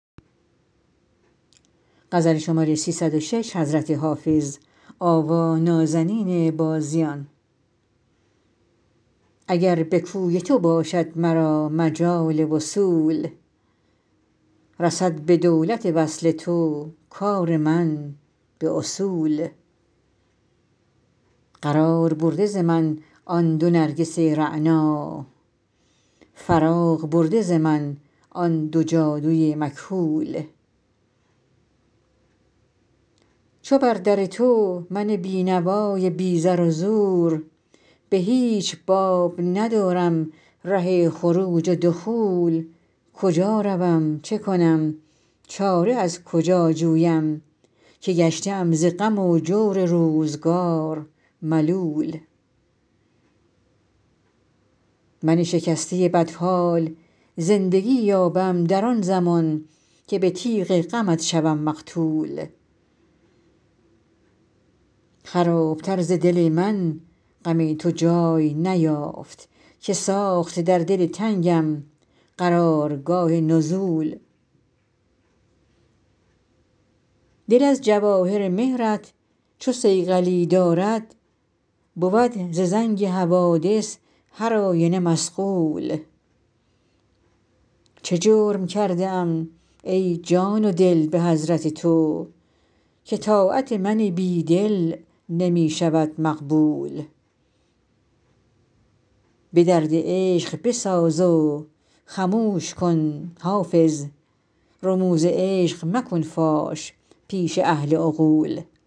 حافظ غزلیات غزل شمارهٔ ۳۰۶ به خوانش